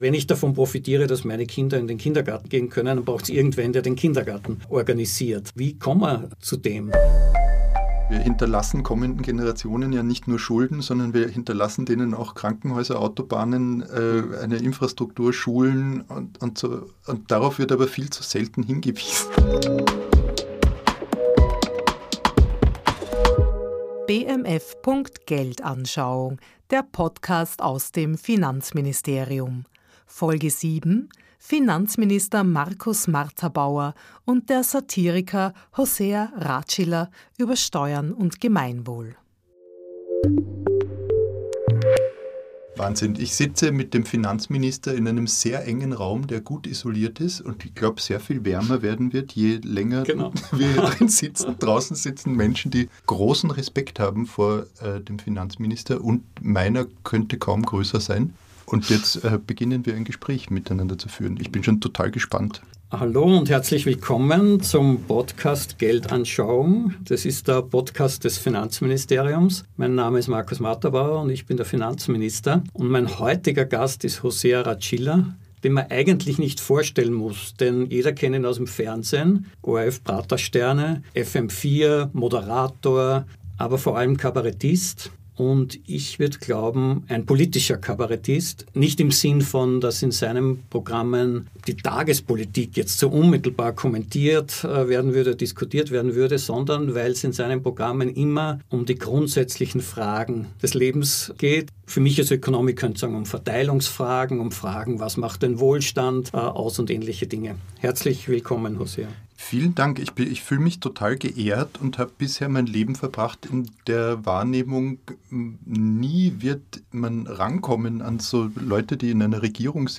#7 Finanzminister Markus Marterbauer und der Satiriker Hosea Ratschiller über Steuern und Gemeinwohl ~ BMF.Geldanschauung Podcast
Ein Gespräch über Kompromisse, wie der Zusammenhalt der Gesellschaft organisiert werden kann und was Steuern damit zu tun haben.